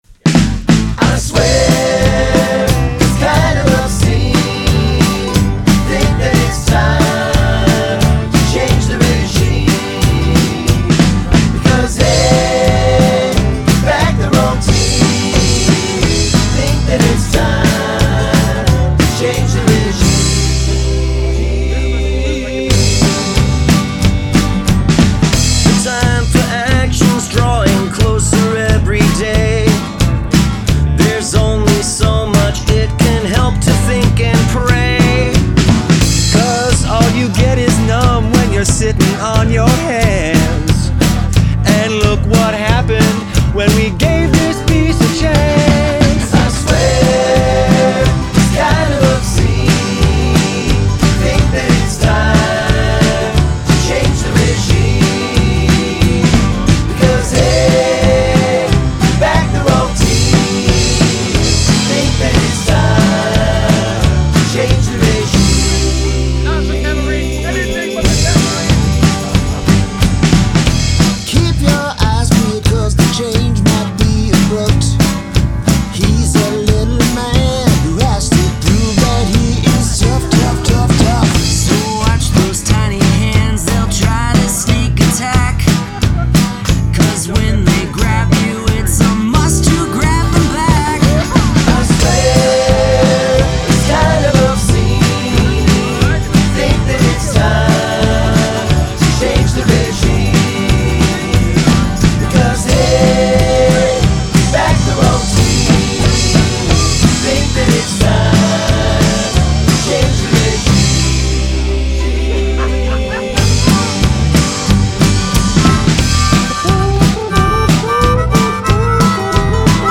Mandolin! Mouthtrumpet! Wacky distorted sounds!
A party in a song.
The performance and audio are perfect.